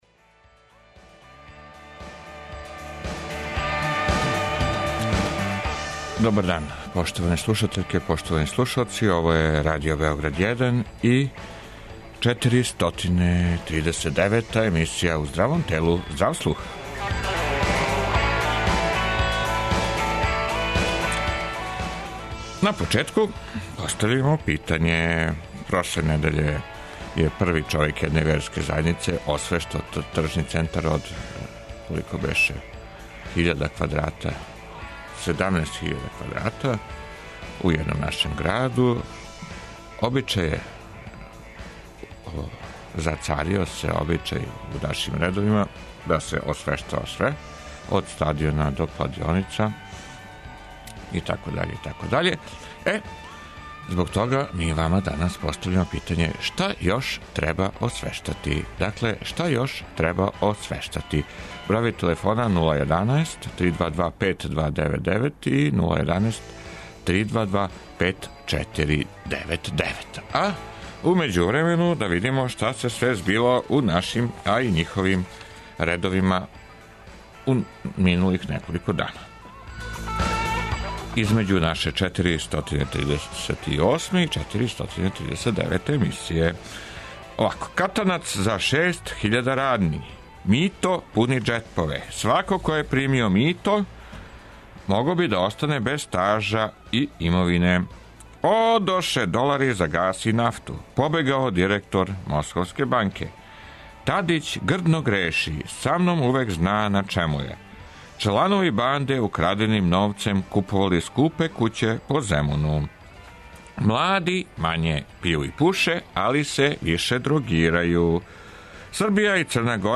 Уколико желите да се укључите у наш програм и да се обратите широком аудиторијуму, ова емисија је идеална прилика. Наравно, да би сте учествовали морате знати одговор на питање недеље, које овога пута гласи: Шта још треба освештати?